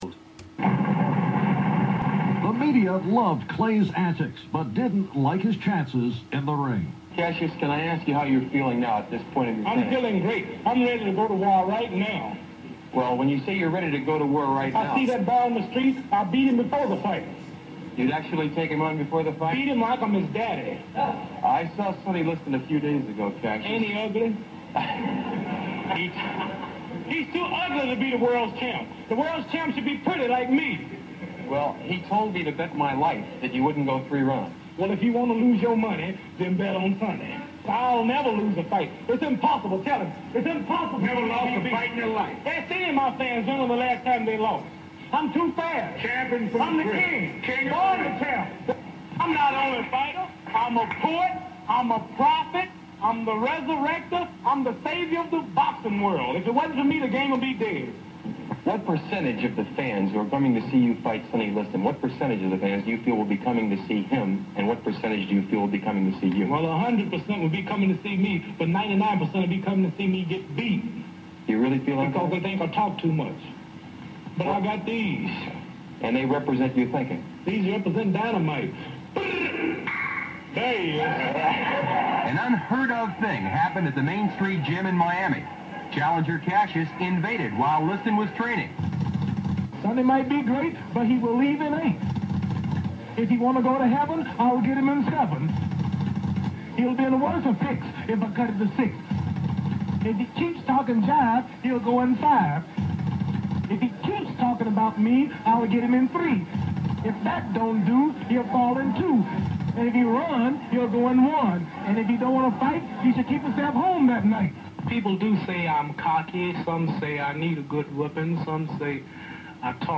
Interview with Muhammad Ali prior to winning first heavyweight title 50 years ago today.